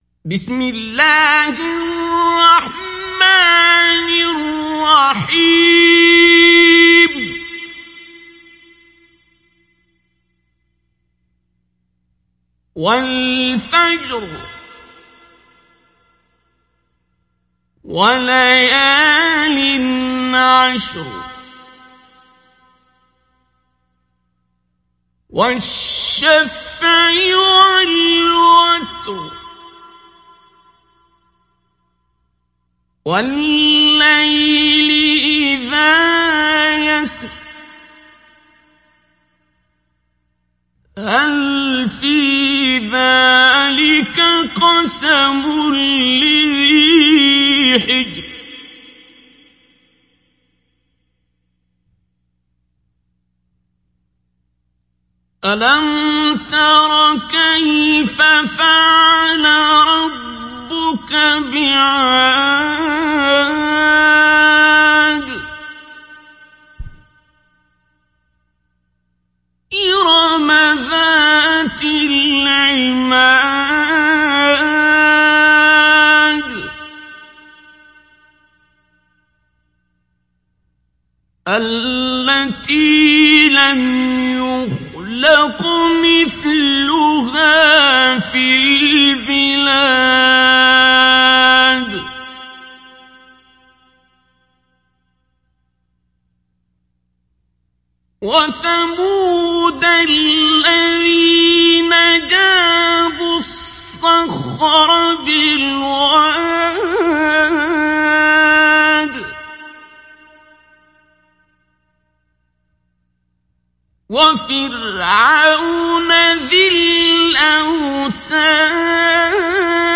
Surah Al Fajr Download mp3 Abdul Basit Abd Alsamad Mujawwad Riwayat Hafs from Asim, Download Quran and listen mp3 full direct links
Download Surah Al Fajr Abdul Basit Abd Alsamad Mujawwad